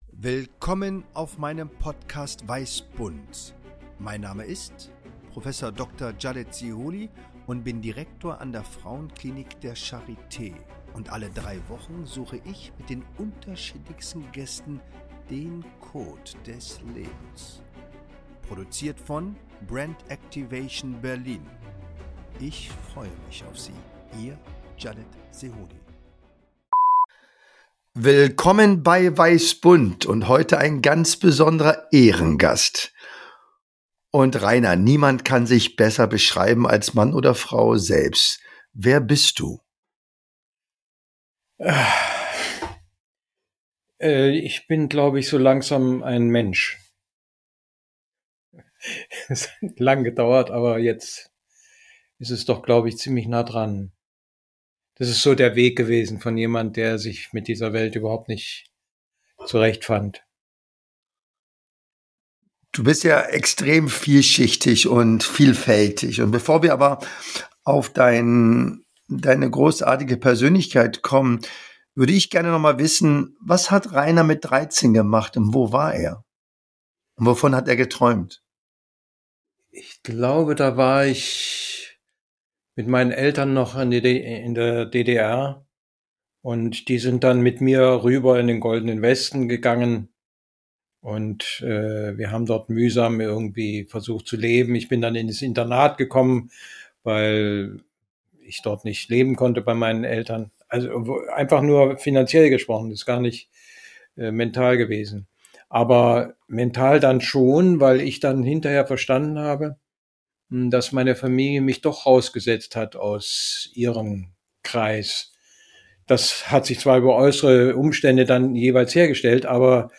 Spontan, intuitiv, ohne Skript, Improvisation pur!